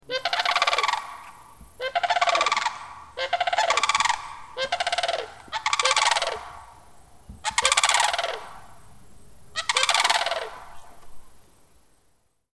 High flying and loud
Click below for the sounds of one Sandhill Crane.
sandhill-crane-sound.mp3